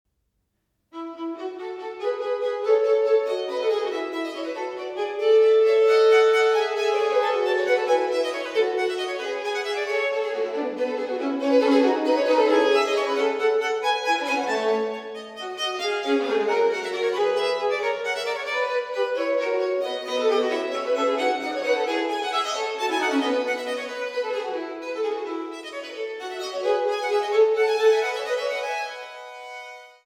Instrumetalmusik für Hof, Kirche, Oper und Kammer
für drei Soloviolinen